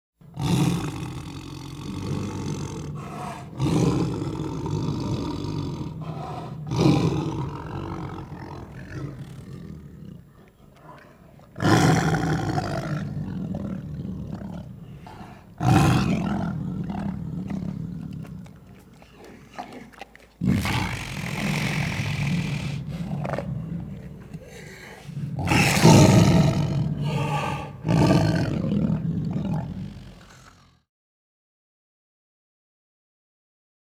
animal
Tiger Growls with Smacks